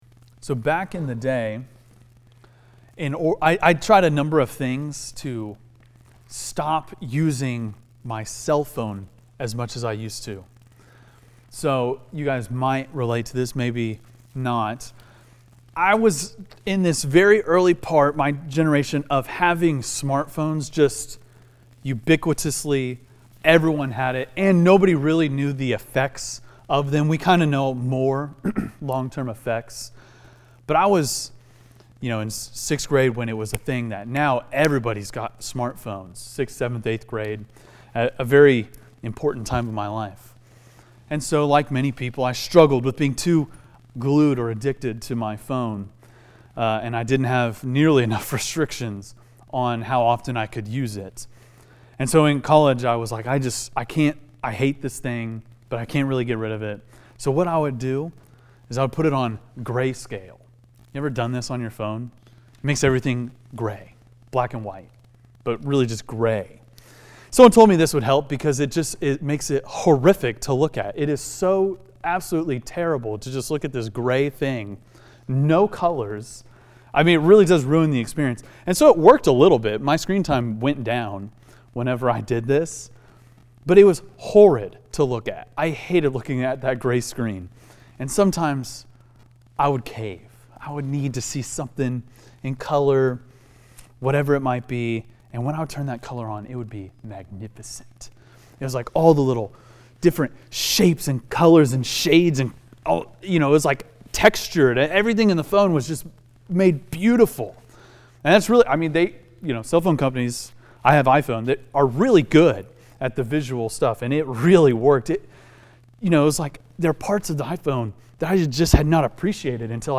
Arrow Heights Students The Offices of the Son of God Feb 17 2025 | 00:28:52 1x 00:00 / 00:28:52 Subscribe Share Apple Podcasts Spotify Overcast RSS Feed Share Link Embed